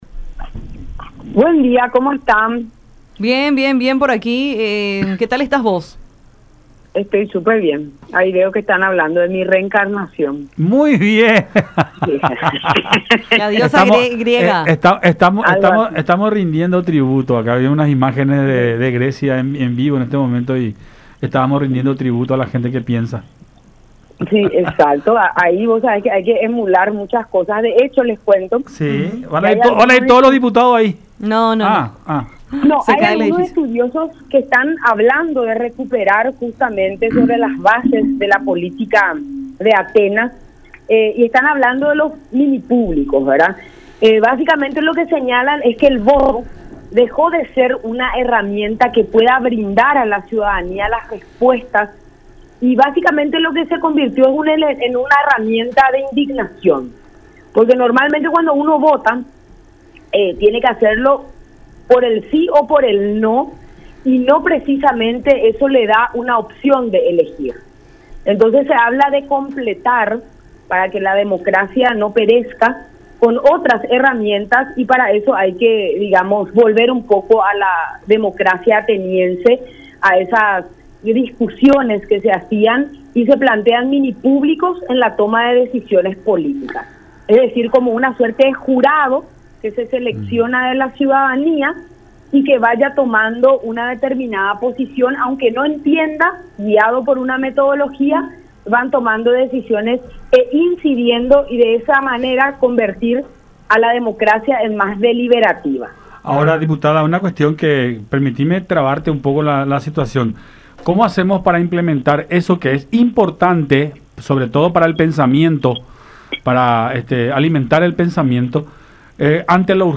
Si mañana aprobamos, justito llegamos al último día de sesión en la que podrá votarse la intervención”, explicó González en diálogo con Enfoque 800 por La Unión.